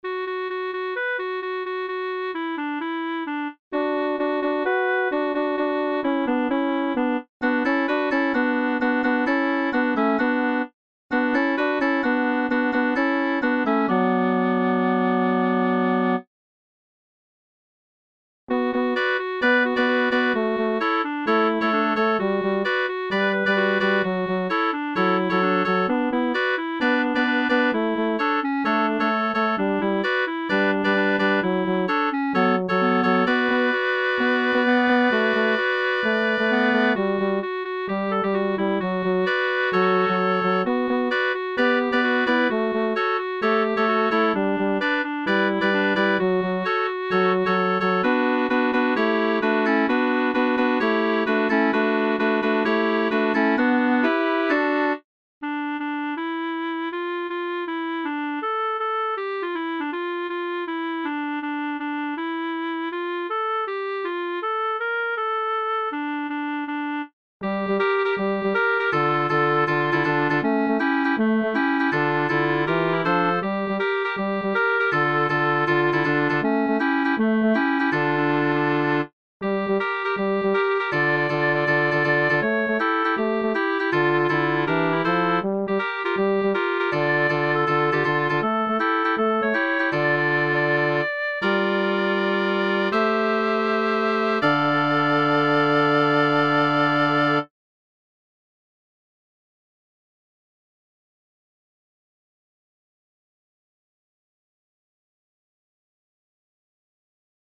Midi Preview